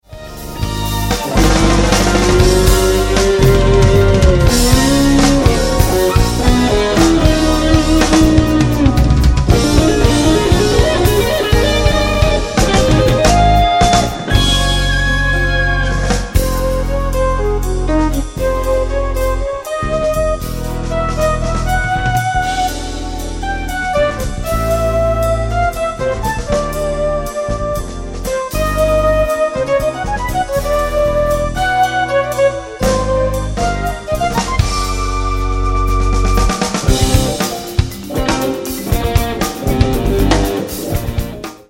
Claviers
Batterie
Basse
Guitare